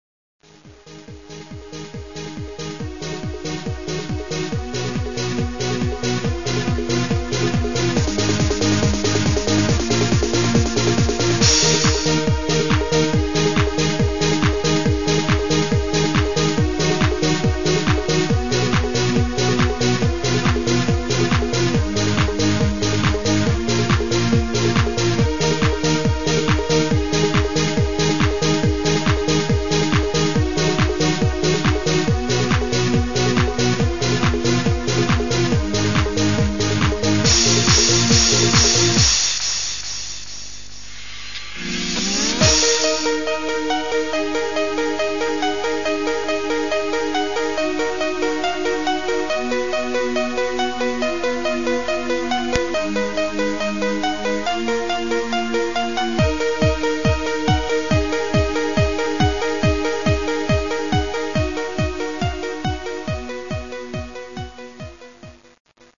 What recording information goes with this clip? • Quality: 22kHz, Mono